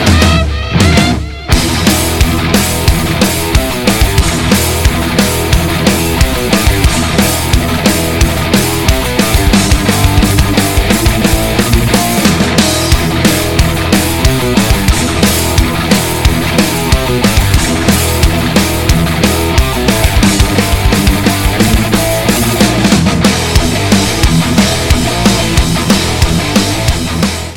• Качество: 192, Stereo
электрогитара
быстрые
Hard rock
Metal
Trash metal
соло на электрогитаре